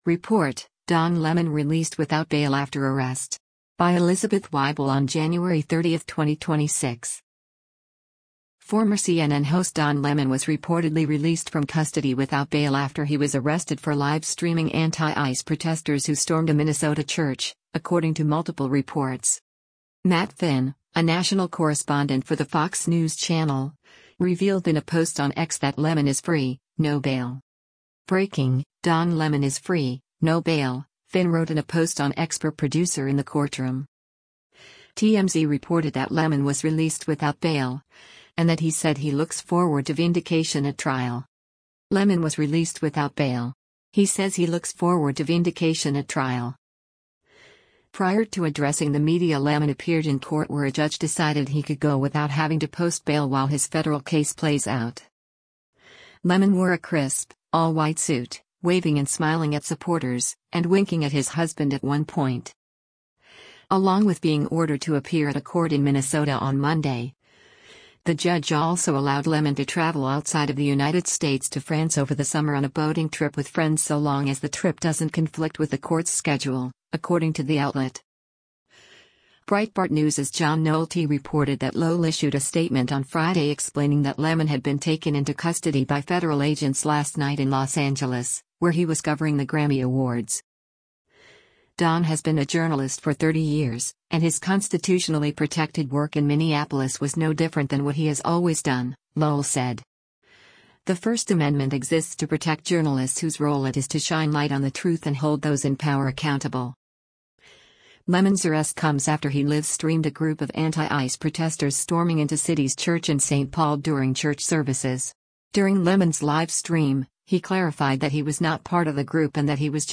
Journalist Don Lemon speaks to members of the media while exiting federal court in Los Ang